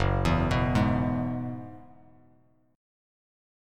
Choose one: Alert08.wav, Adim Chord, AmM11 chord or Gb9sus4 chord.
Gb9sus4 chord